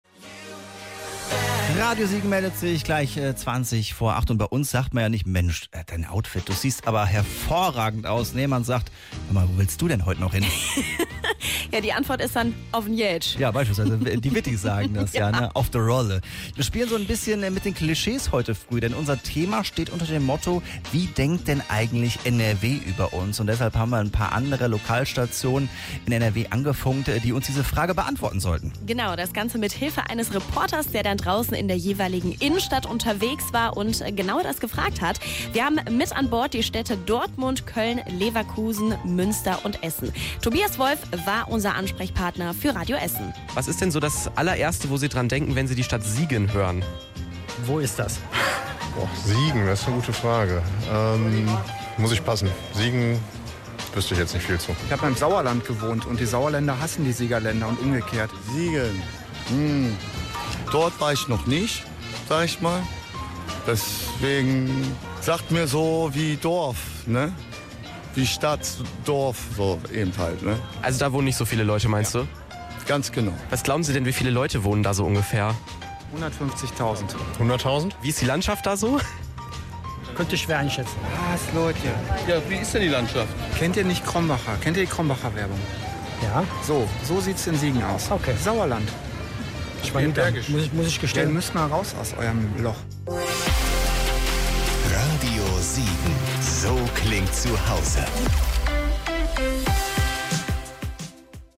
Mit Straßenumfragen aus Köln, Dortmund, Essen, Münster und Leverkusen.